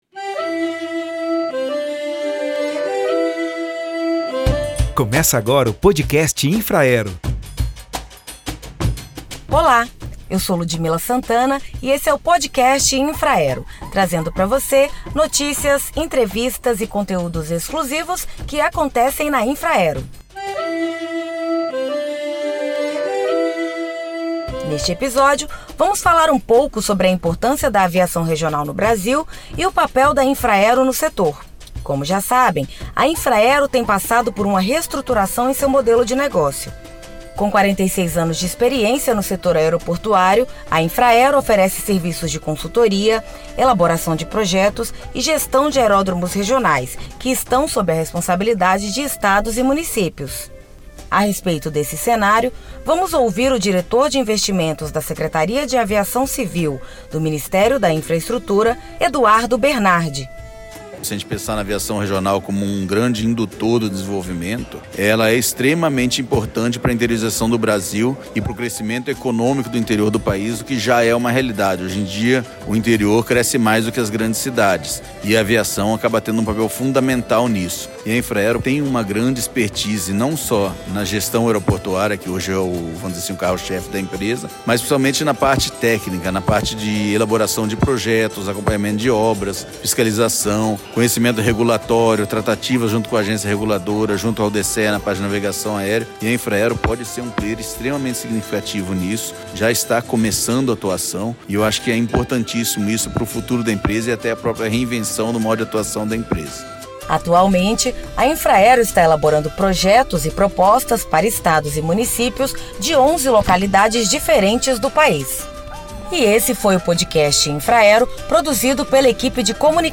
O Podcast desta semana traz uma entrevista com o diretor de Investimentos da Secretaria Nacional de Aviação, do Ministério da Infraestrutura, Eduardo Bernardi, que fala da importância da aviação regional no País e o papel da Infraero para impulsionar o setor.